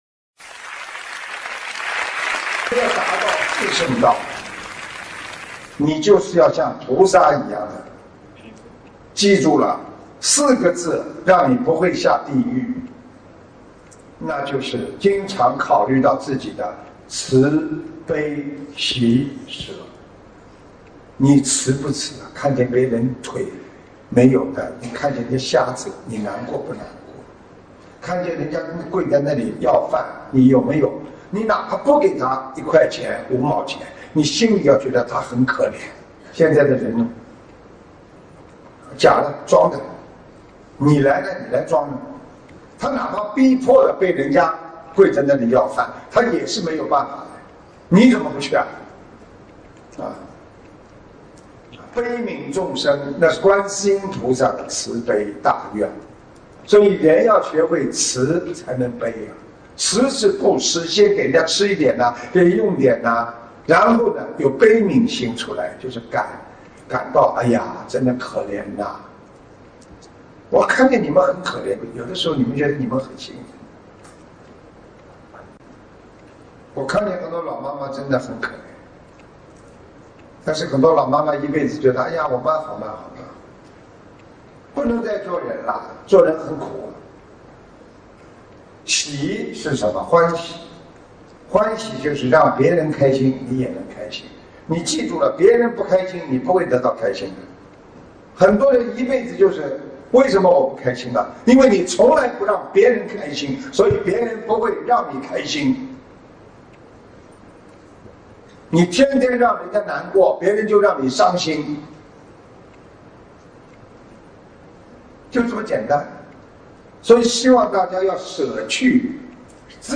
89【慈悲喜舍 以戒为本】-白話佛法广播讲座（视音文） - 新广播讲座 - 心如菩提 - Powered by Discuz!